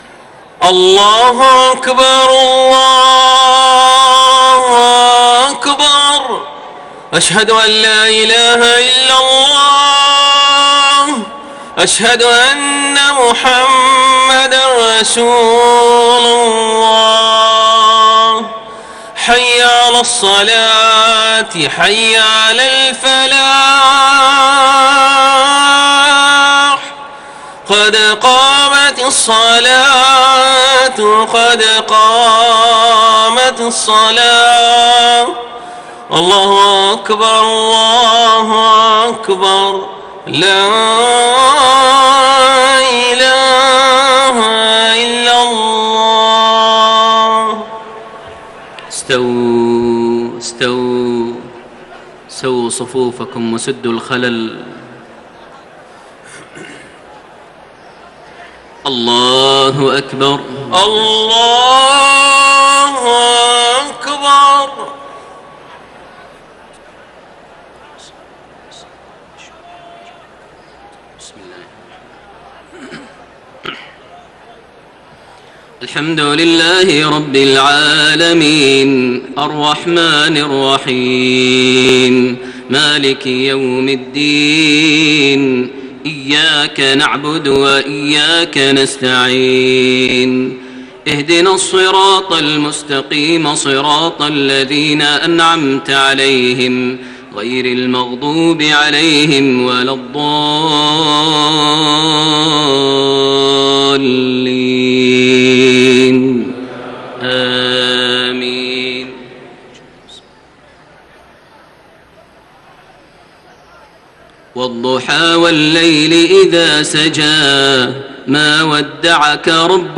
صلاة المغرب 13 ذو الحجة 1432هـ سورتي الضحى و الشرح > 1432 هـ > الفروض - تلاوات ماهر المعيقلي